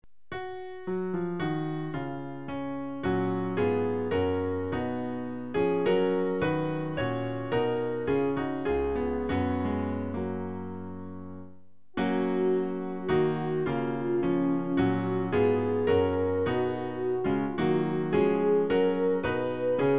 Klavier-Playback zur Begleitung der Gemeinde
MP3 Download (ohne Gesang)
Themenbereich: Jesuslieder